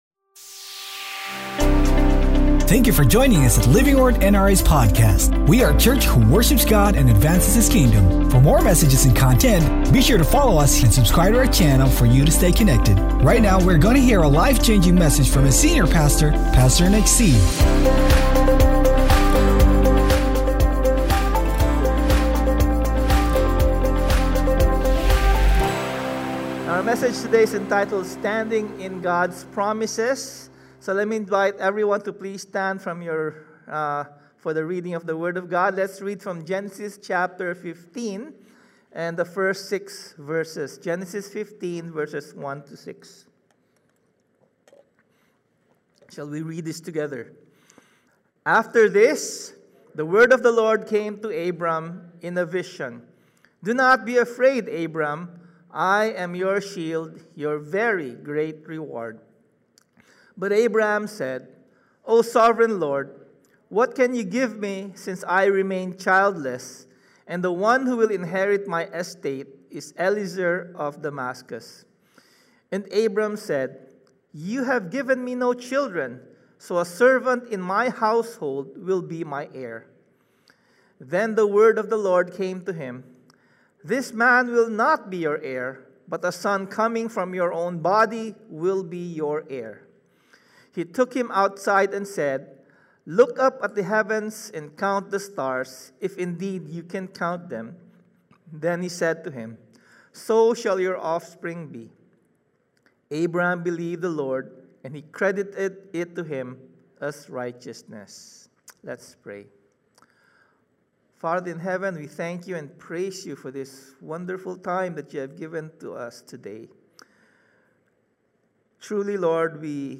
Sermon Title: STANDING IN GOD’S PROMISES Scripture Text: GENESIS 15 Sermon Series: Abraham: Journey of Faith